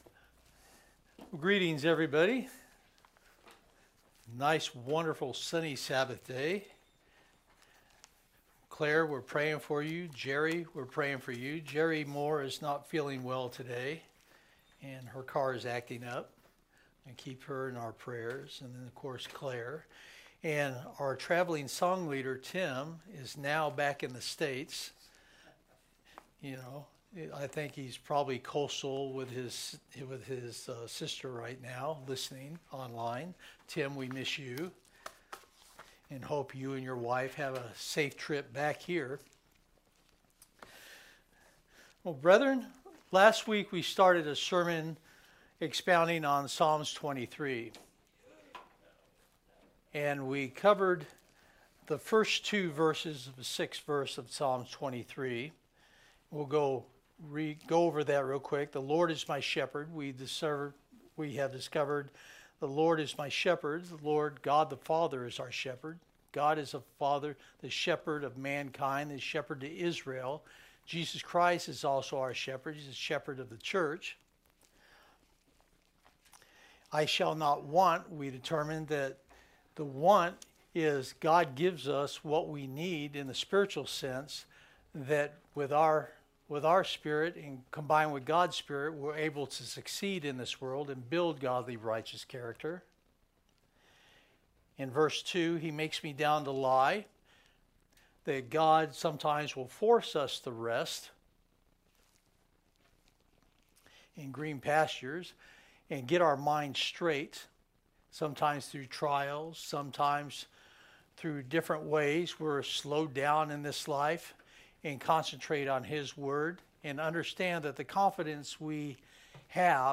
New Sermon | PacificCoG